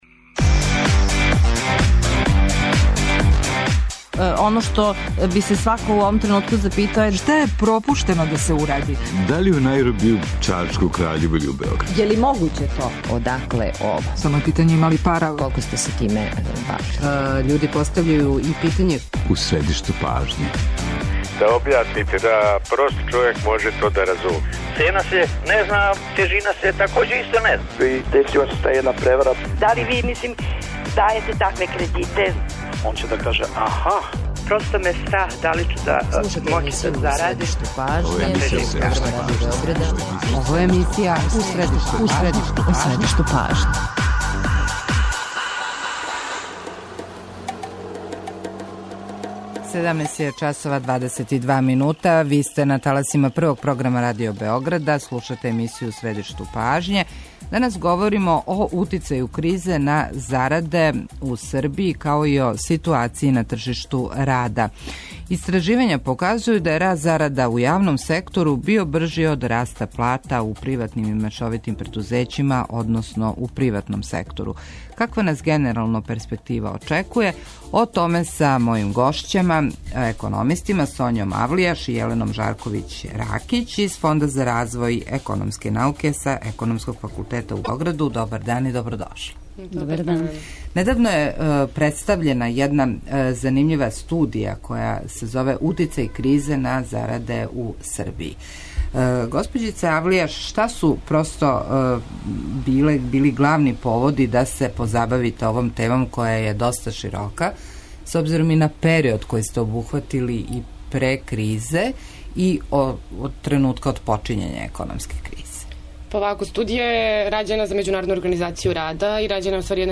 О томе разговарамо са економистима